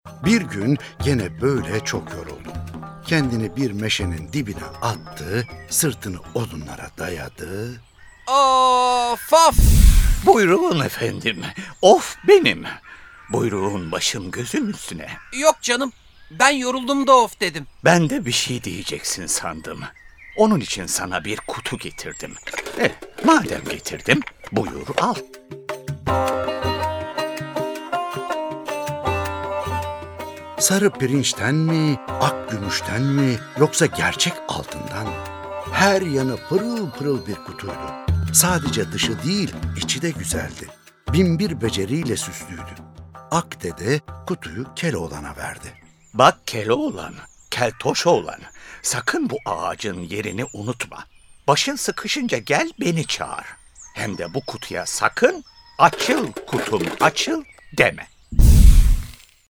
Keloğlan'ın Kutusu Tiyatrosu